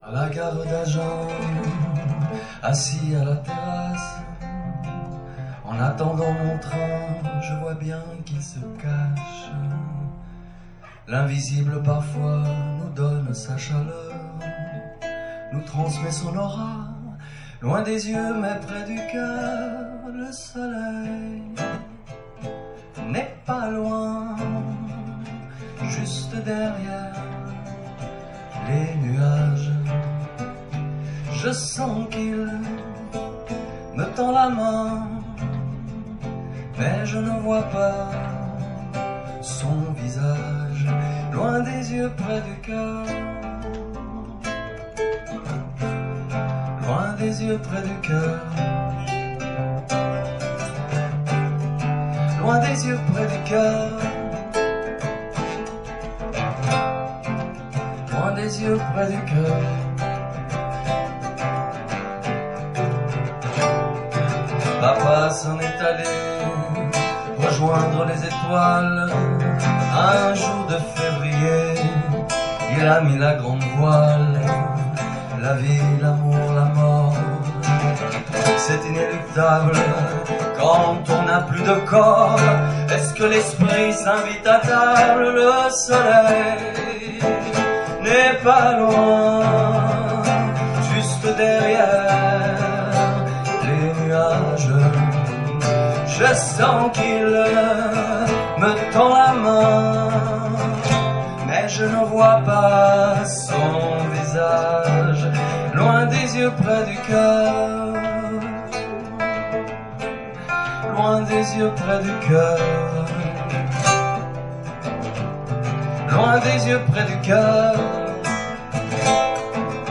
Chez Adel, 3 novembre 2016